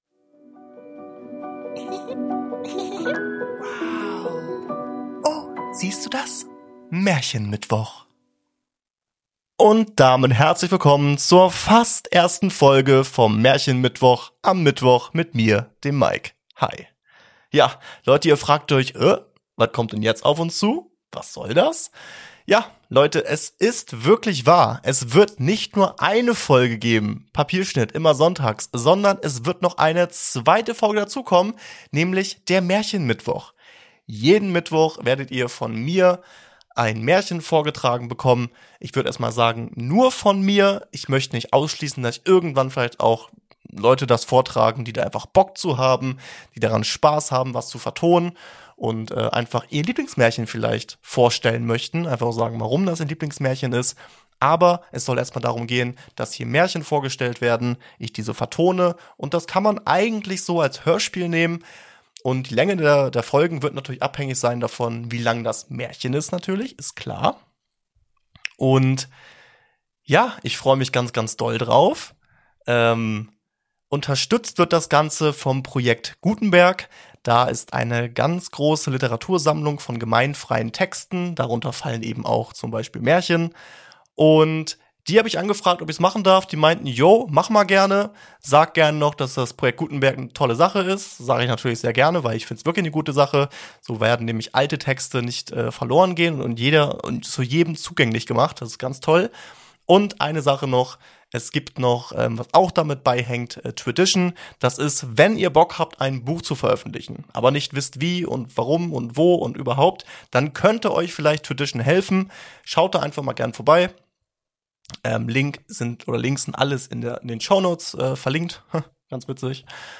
Music Intro -